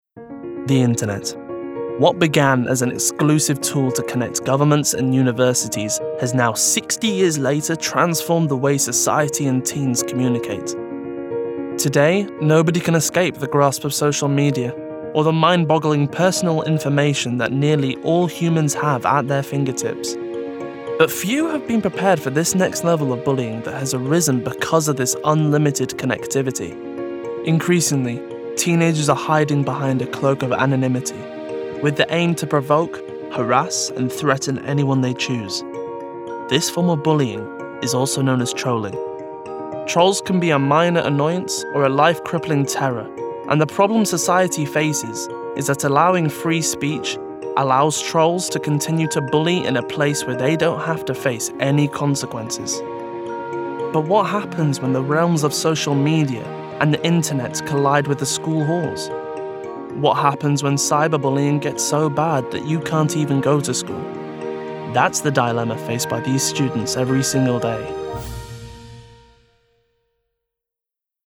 Voice Reel
Documentary - Energised, Clear